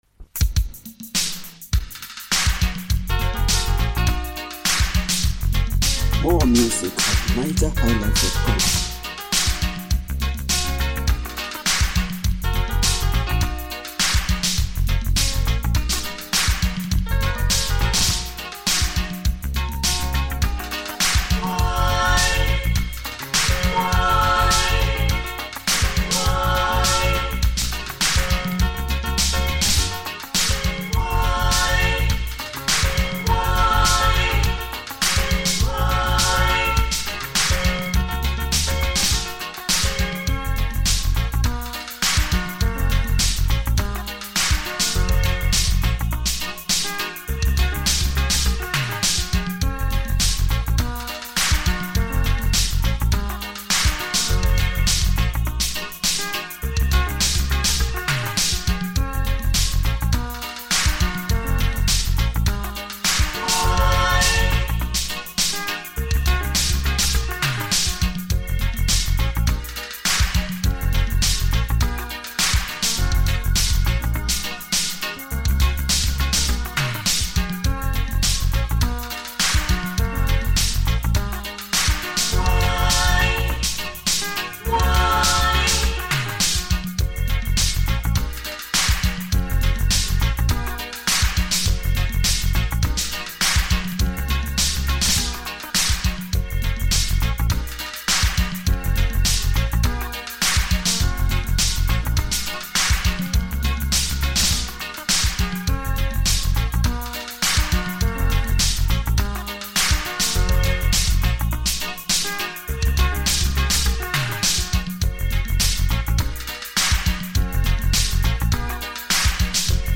Home » Ragae